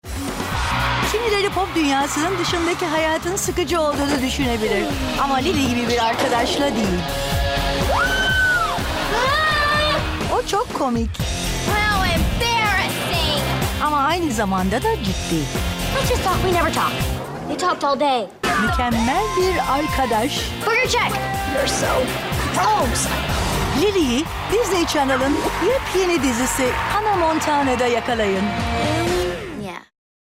Sprechprobe: Sonstiges (Muttersprache):
I do speak Turkish with an Istanbul accent (proper accent such as BBC or Oxford accent in the UK.